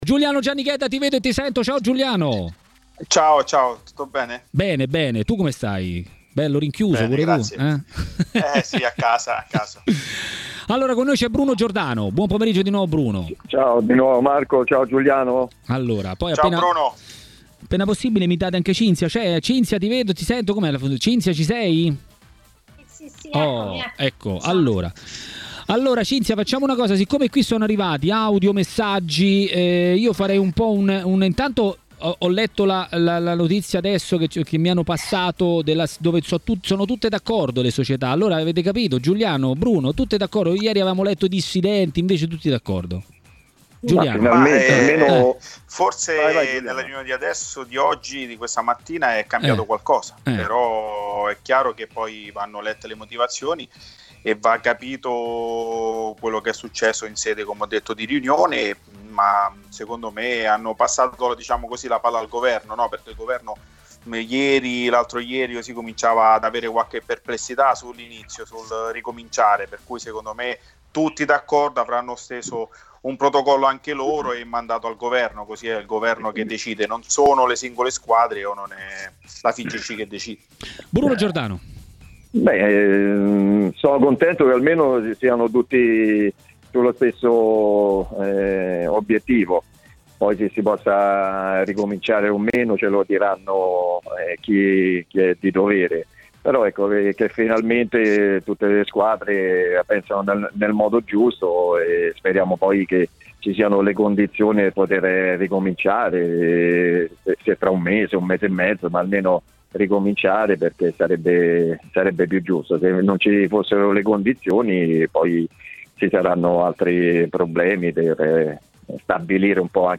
Bruno Giordano, ex calciatore e tecnico, a Maracanà, nel pomeriggio di TMW Radio, ha parlato della possibilità del calcio di ripartire.